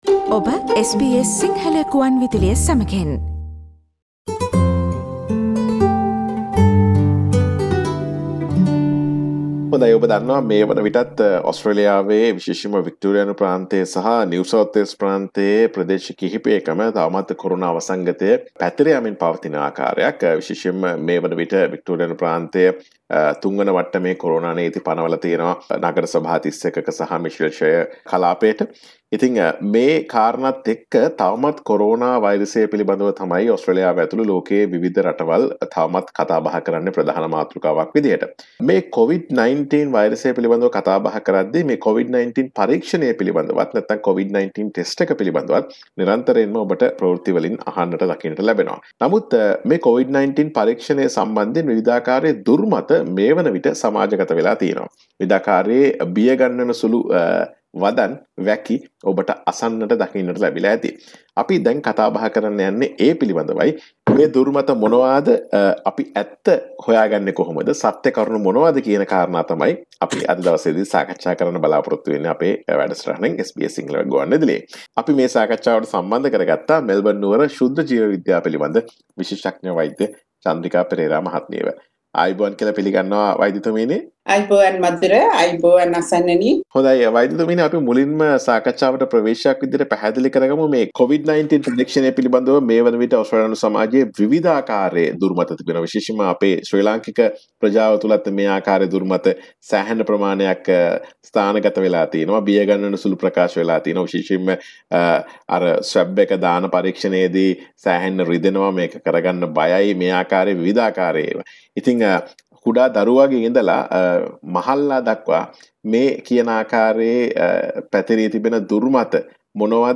It seems to be an increase in testing that has been accompanied by a rise in misinformation and conspiracy theories focusing on the reliability and safety of COVID-19 testing procedures. SBS Sinhala Radio interview